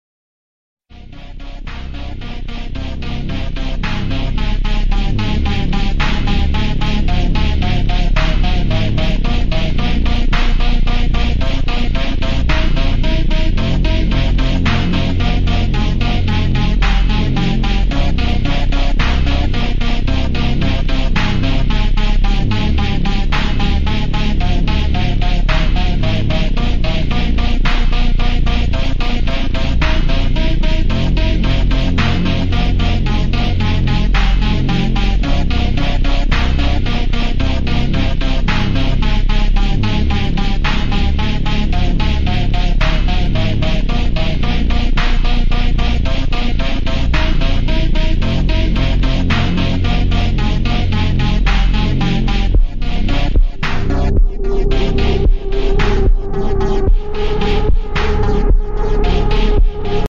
Honda Crash Contro La Lamborchini Sound Effects Free Download
honda crash contro la lamborchini a 256 km/h!